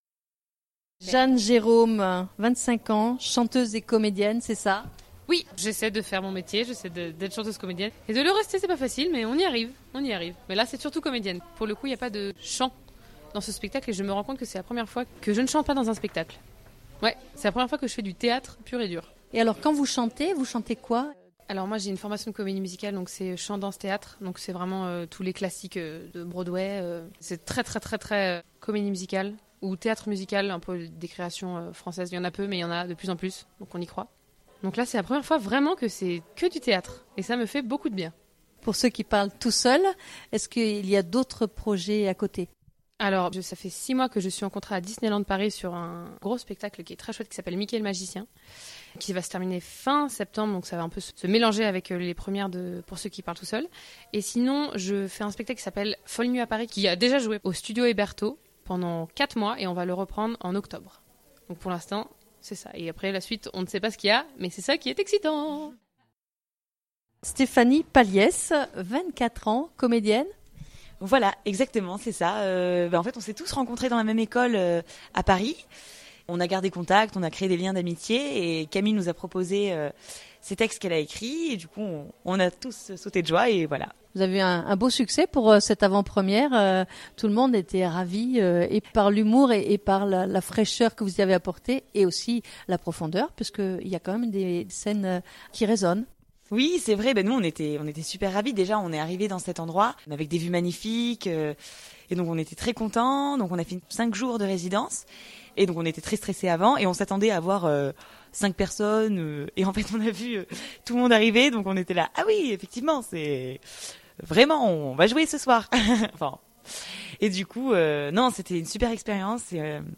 Rencontre avec 3 des comédiens de la Compagnie de théâtre "C'est pas dramatique"